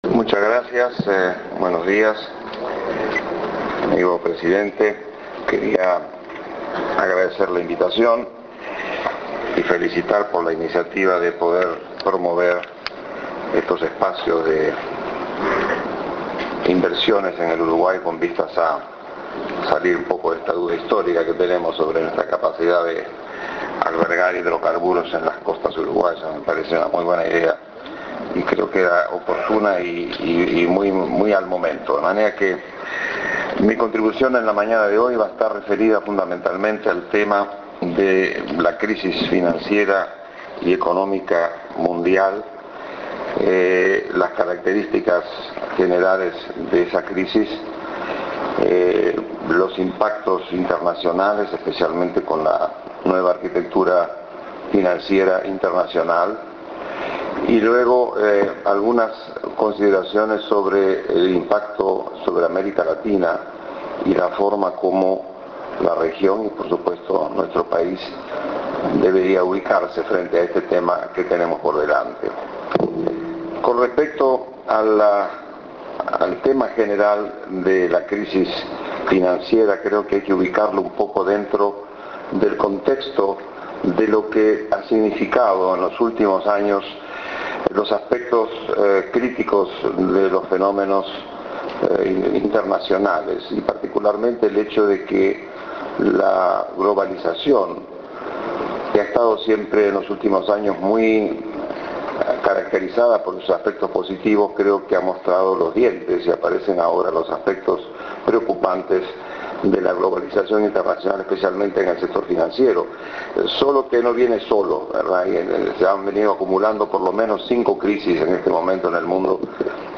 Palabras de Enrique Iglesias Escuchar MP3